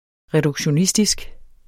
Udtale [ ʁεdugɕoˈnisdisg ]